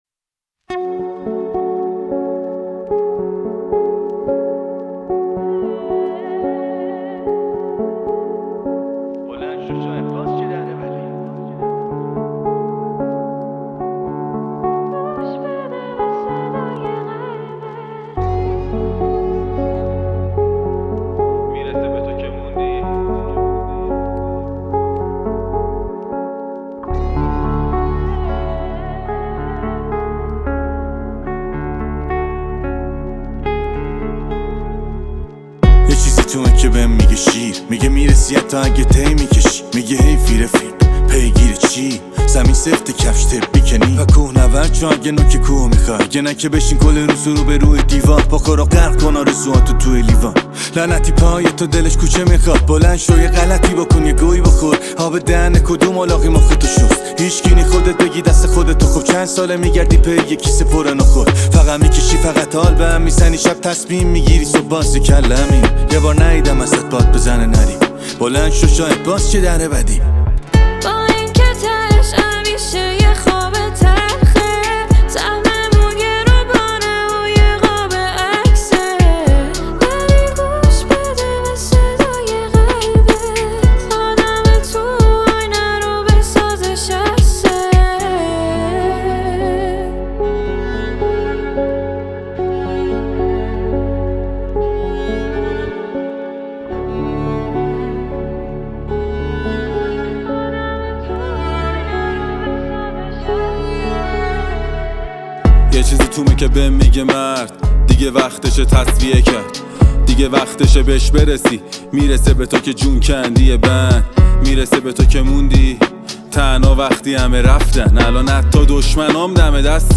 رپر محبوب و پرطرفدار